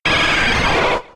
Cri de Nosferalto K.O. dans Pokémon X et Y.